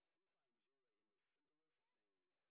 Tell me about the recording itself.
sp07_exhibition_snr20.wav